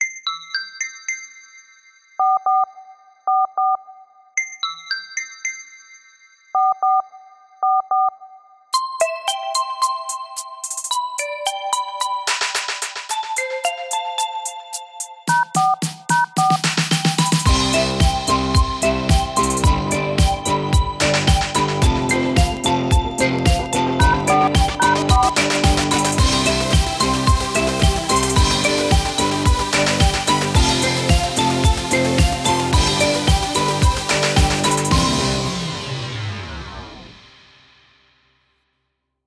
Ringtone - Alarm Clock
FREE scalable happy pop-rock musical RINGTONE